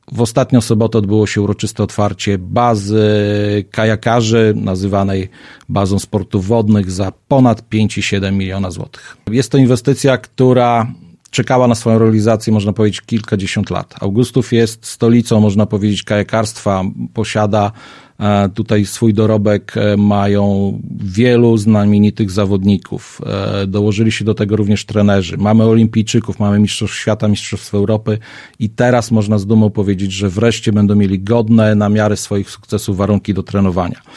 Baza Sportów Wodnych powstała w Augustowie. W sobotę (28.09.19) obyło się uroczyste otwarcie, a we wtorek (01.10.19) Mirosław Karolczuk, burmistrz miasta opowiedział w Radiu 5 o tej inwestycji.